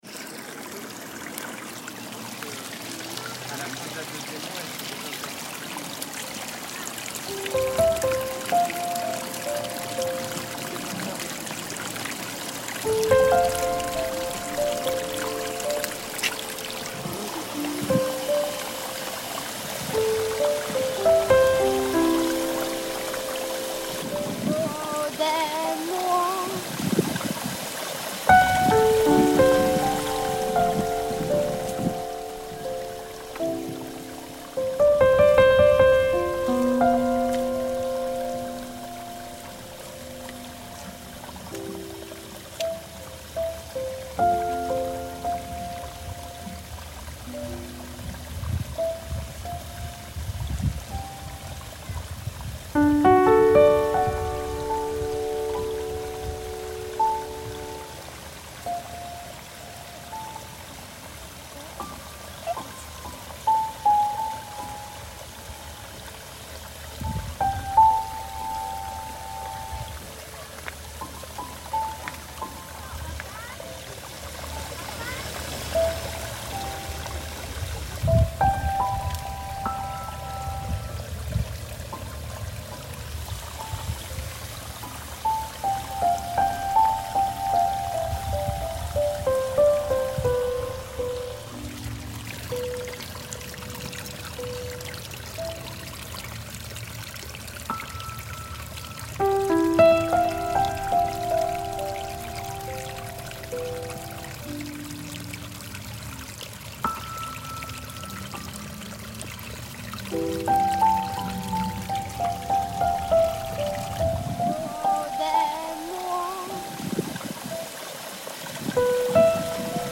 « Offrons-nous la possibilité d’entendre l’écho de la voix de cette petite fille, au cœur de la nature, pour l’éternité !
Le son du ruissellement de l’eau et du vent s’enchevêtrant dans ces notes de piano continueront à retentir comme un écho à l’appel de la nature.
Caractéristiques techniques : son de nature enregistré au jardin des plantes à Paris, accompagné de piano.